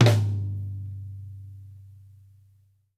SNARE+MED -R.wav